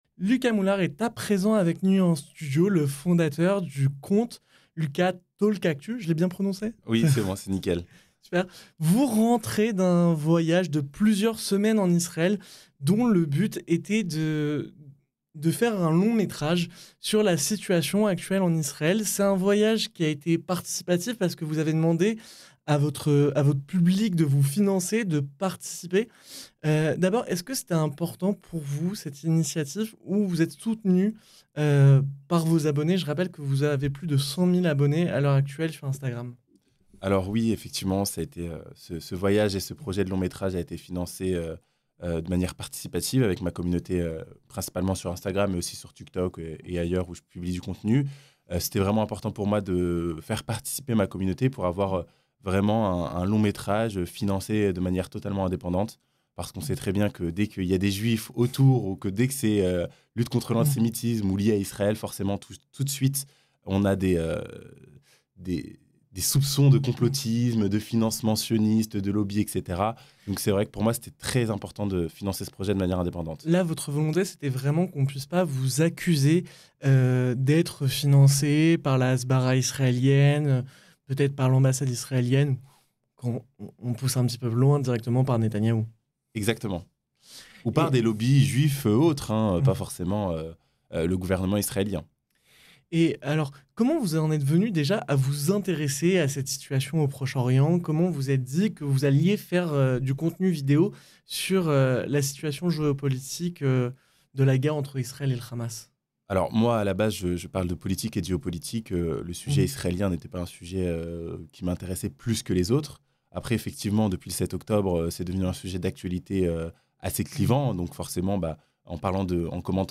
Un entretien à retrouver en intégralité sur notre page YouTube.